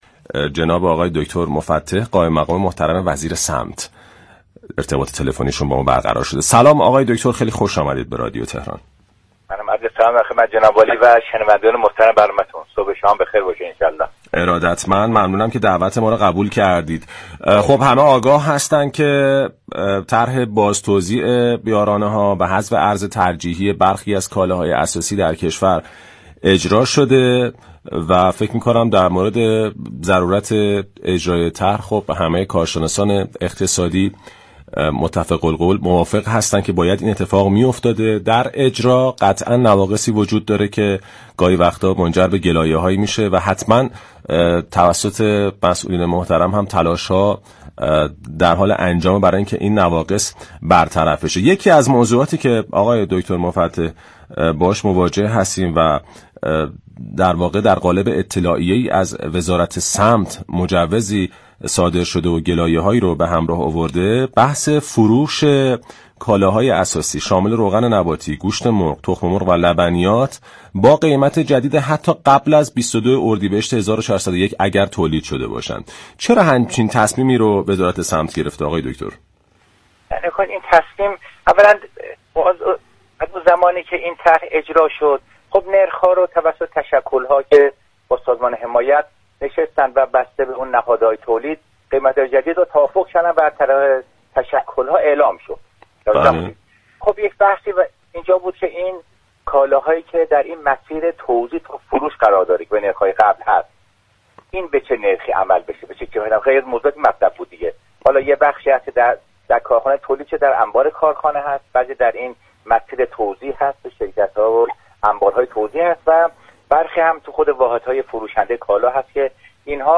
محمدصادق مفتح قائم مقام وزیر صمت در گفتگو با بازار تهران رادیو تهران در خصوص این تصمیم وزارت صمت گفت: